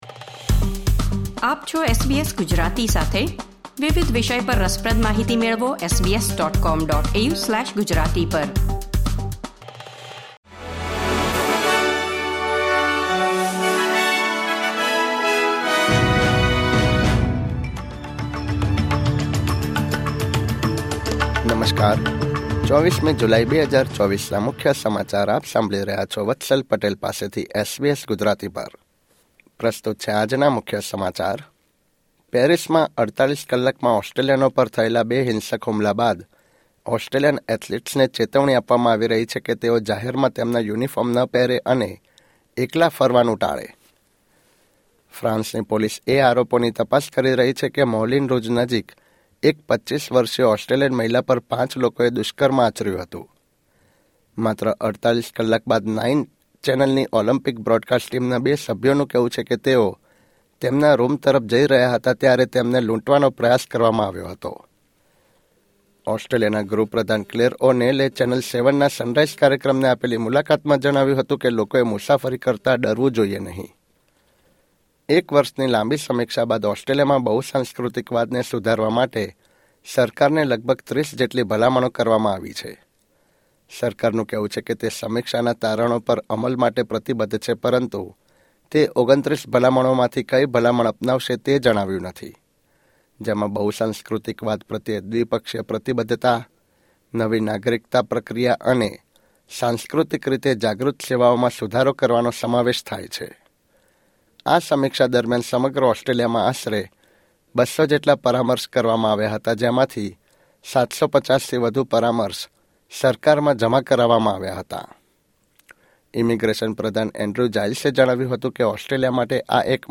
SBS Gujarati News Bulletin 24 July 2024